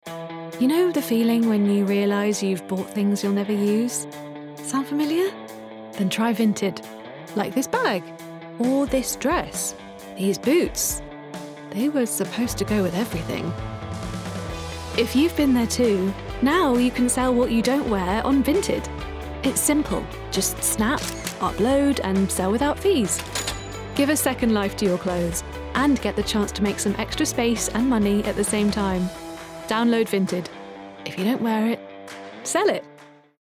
Neutral/London, Confident/Engaging/Soothing